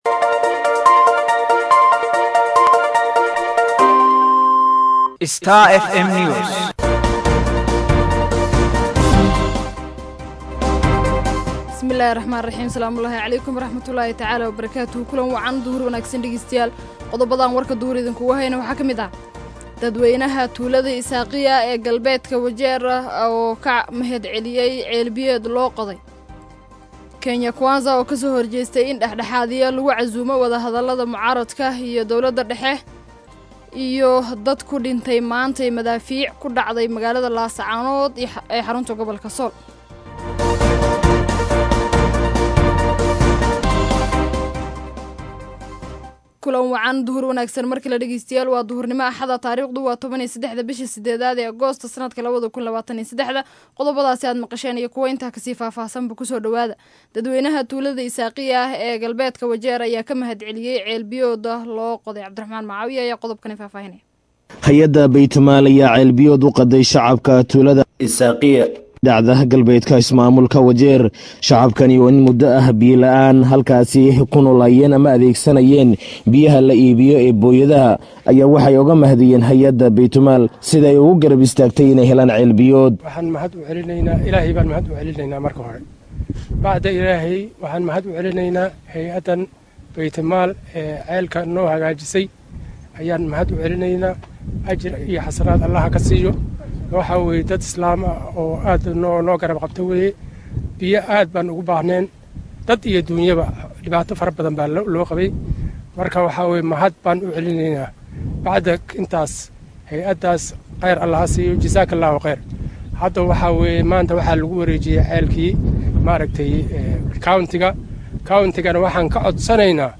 DHAGEYSO:WARKA DUHURNIMO EE IDAACADDA STAR FM